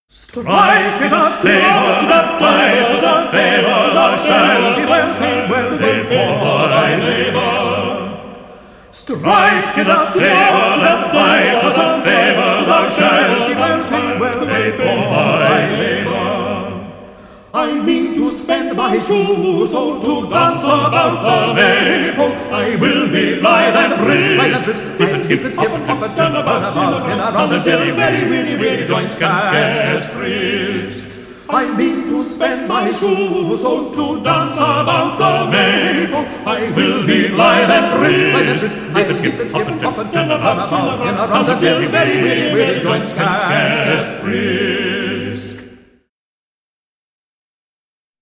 «Στράικ ιτ απ, Τάμπορ» (“Strike it up, Tabor” – «Aς αρχίσει το τραγούδι, Tάμπορ») του Τόμας Γουίλκς (Thomas Weelkes) αποτελεί παράδειγμα πολυφωνικής φωνητικής μουσικής από τη δυτικοευρωπαϊκή μουσική παράδοση